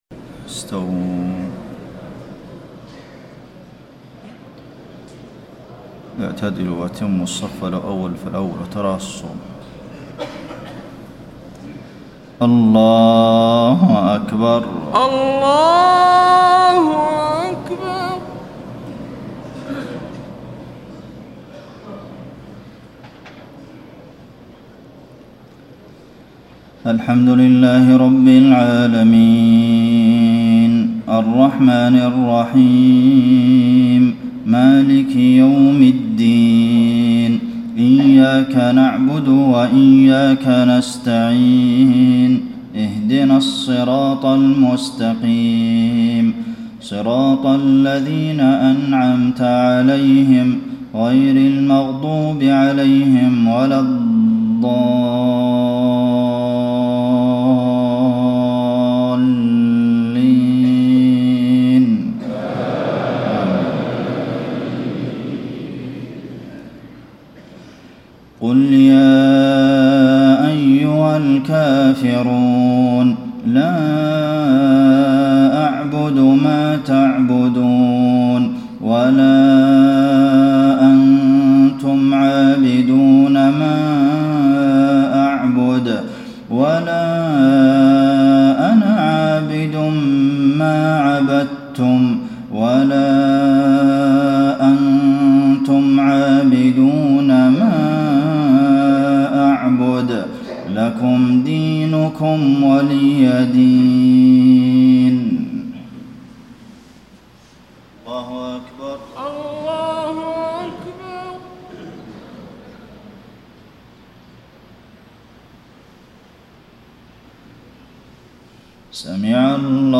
صلاة المغرب 4-8-1434 من سورتي الكافرون و النصر > 1434 🕌 > الفروض - تلاوات الحرمين